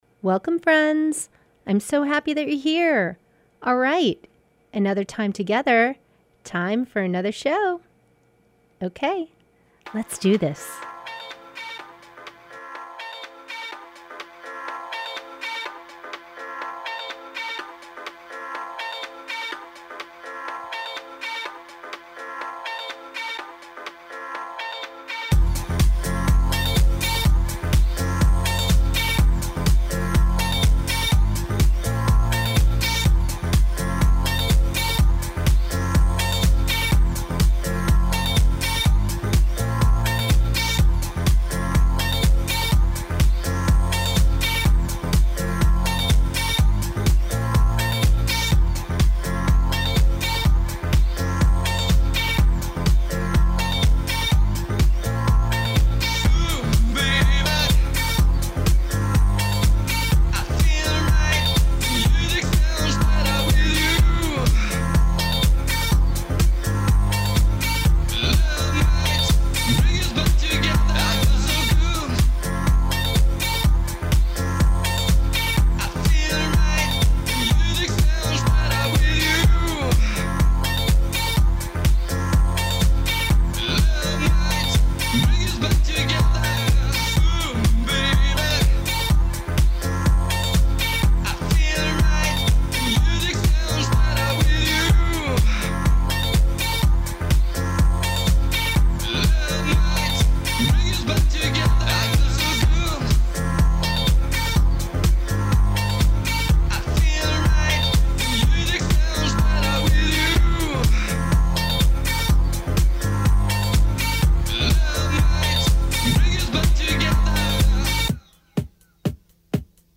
Broadcast every Thursday evening from 6:30 to 8pm on WTBR